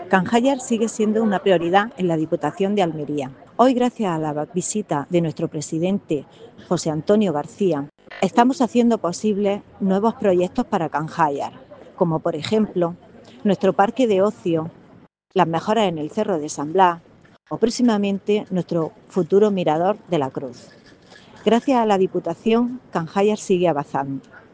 Audio-Alcaldesa-Canjayar.mp3